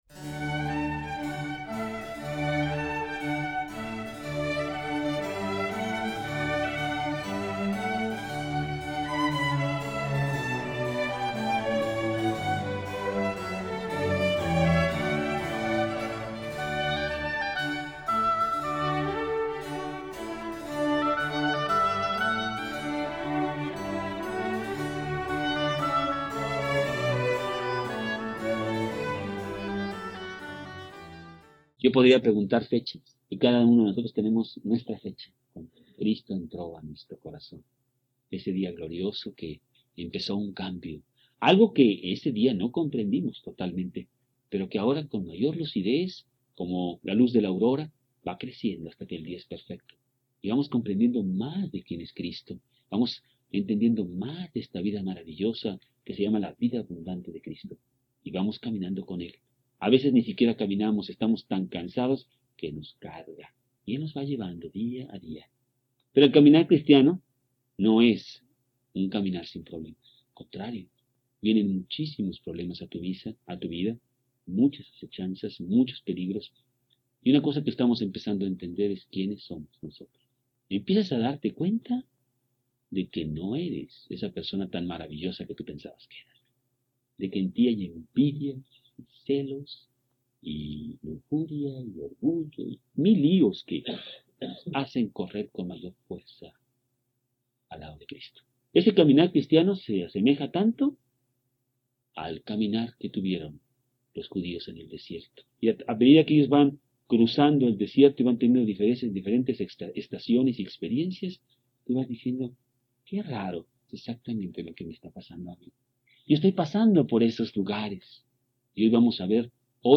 Caminar Cristiano VI Encuentro En Sinaí – 1993 Preacher